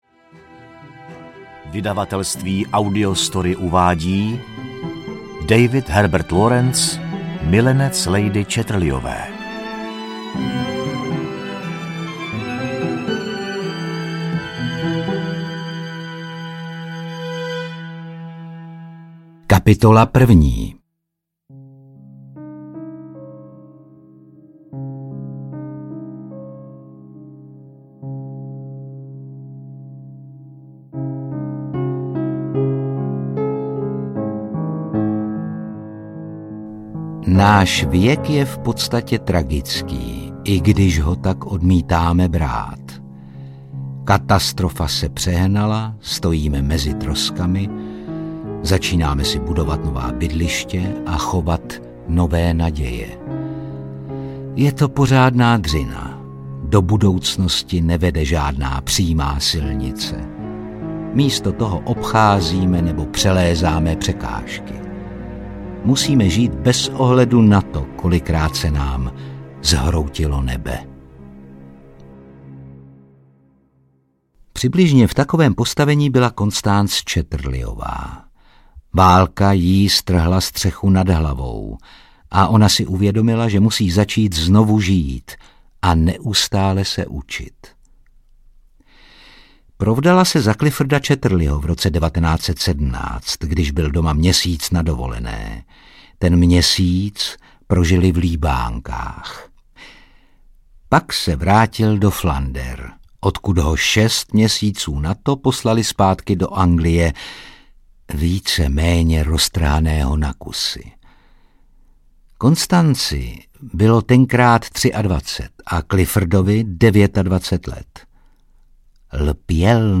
Milenec lady Chatterleyové audiokniha
Ukázka z knihy
milenec-lady-chatterleyove-audiokniha